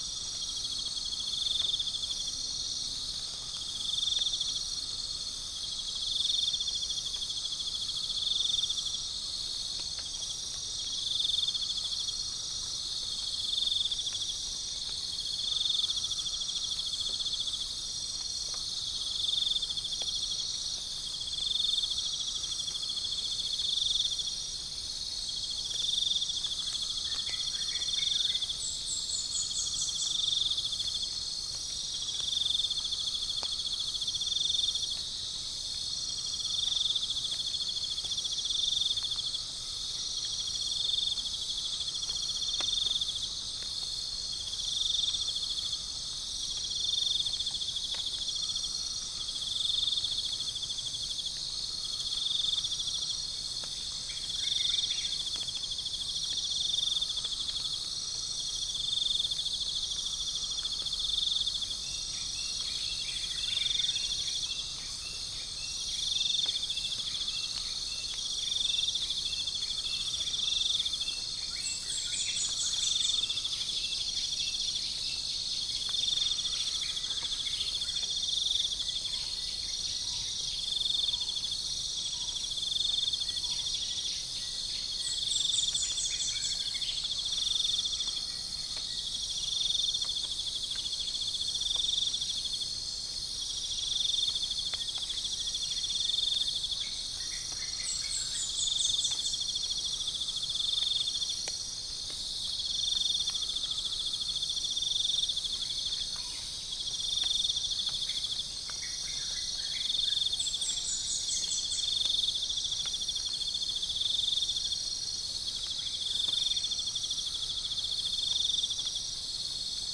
Geopelia striata
Gallus gallus
Centropus bengalensis
Pycnonotus goiavier
Orthotomus sericeus
Cacomantis merulinus
Pycnonotus aurigaster
Prinia familiaris
Dicaeum trigonostigma